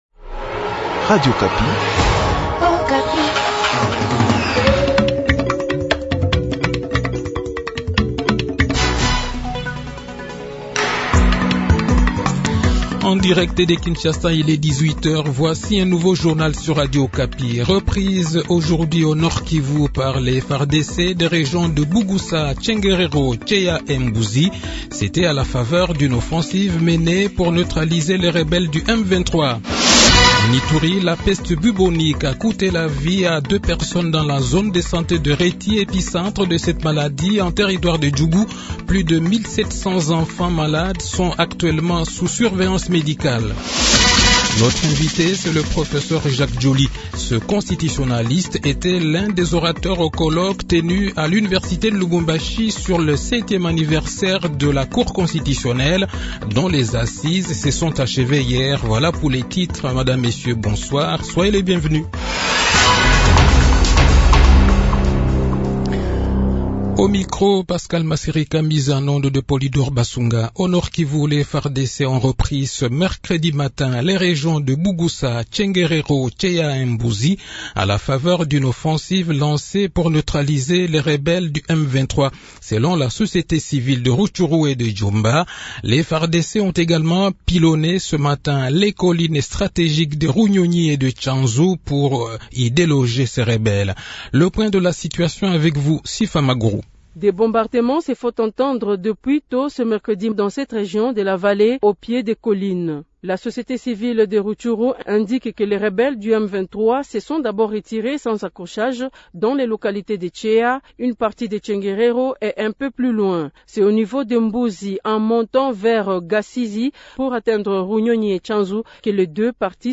Le journal de 18 h, 6 Avril 2022